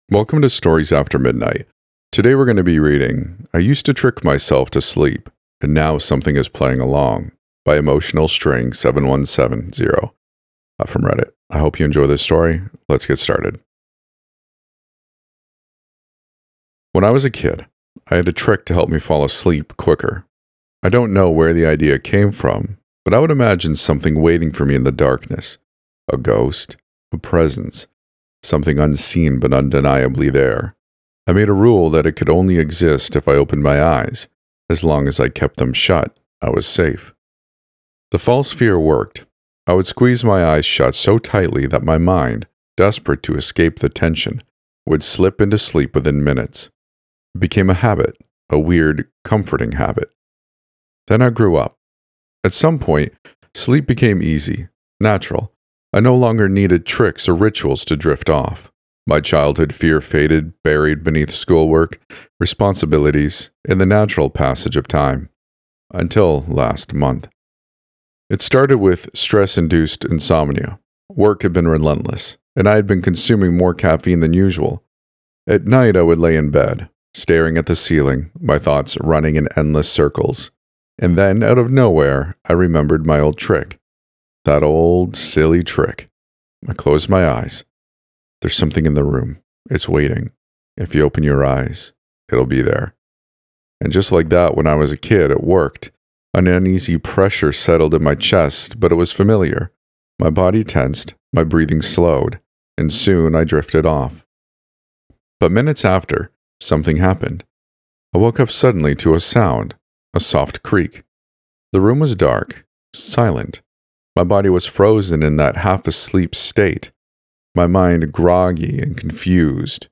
E514 | I used to trick myself to sleep, and now something is playing along | Horror fiction | Not Ai